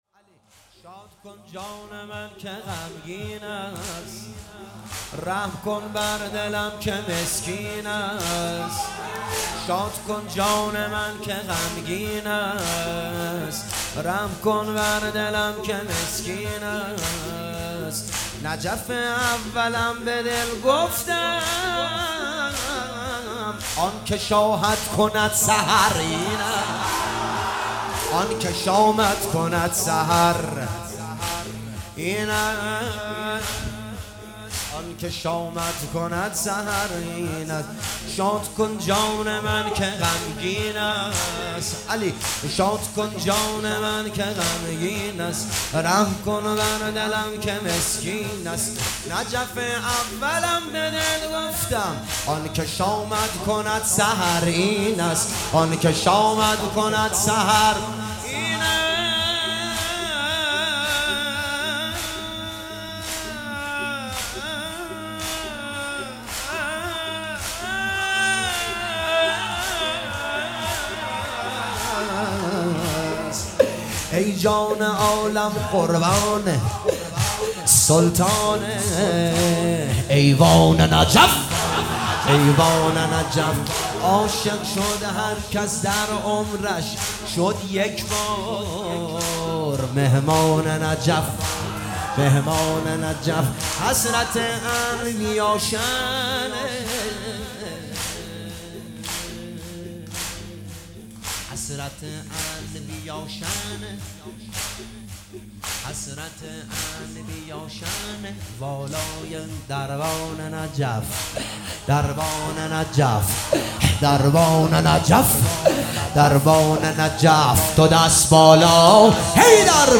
مداحی زمینه شب 19 رمضان شب قدر